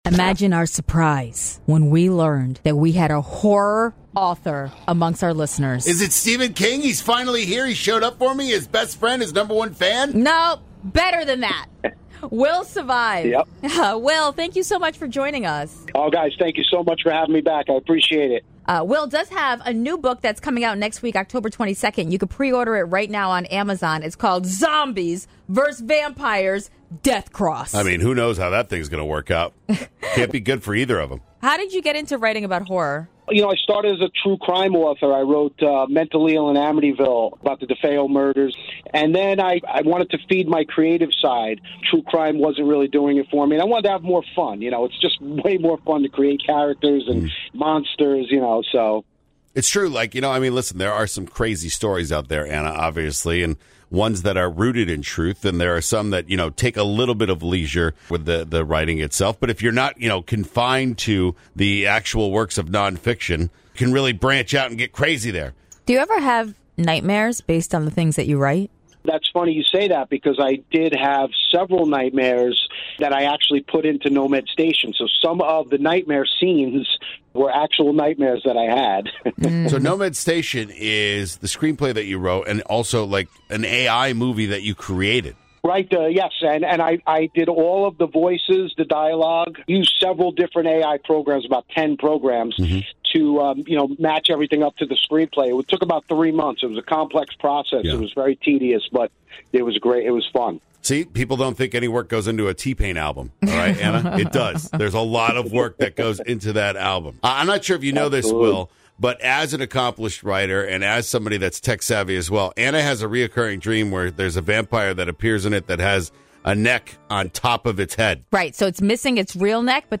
A surprising call came into the show!